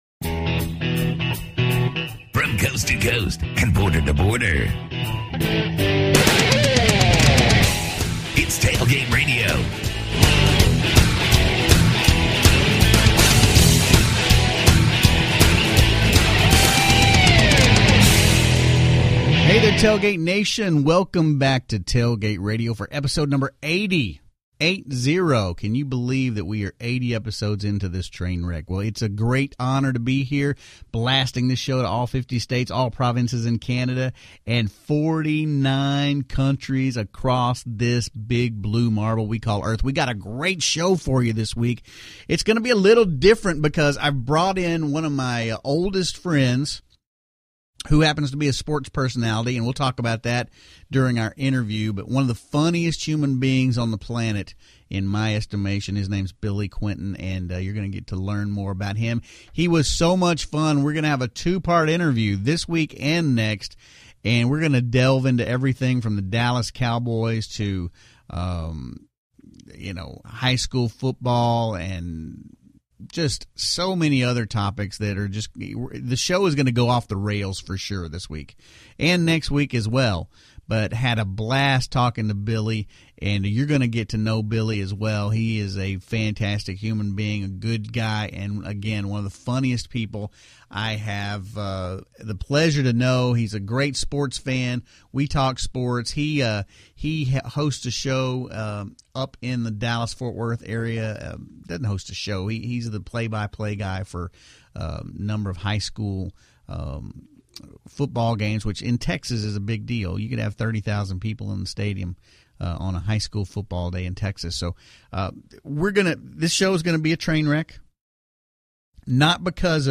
This is part one of a two part interview.